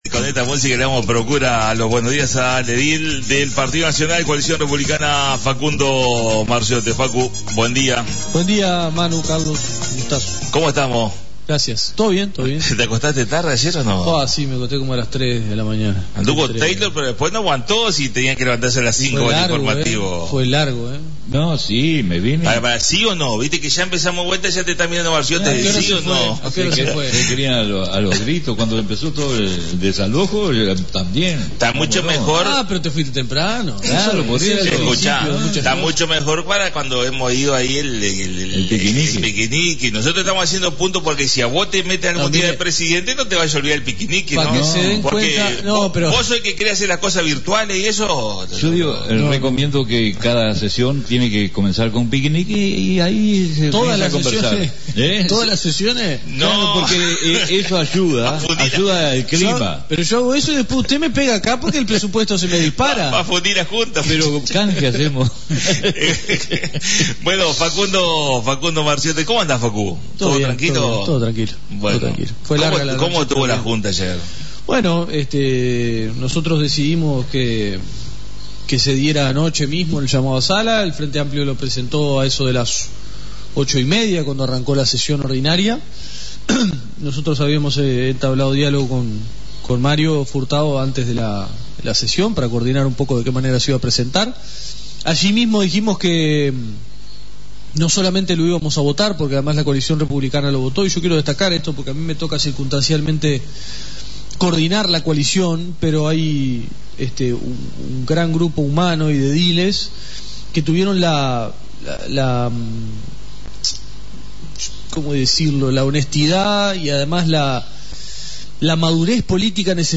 La visita a la Radio de Facundo Marziotte Edil Partido Nacional Coalición Republicana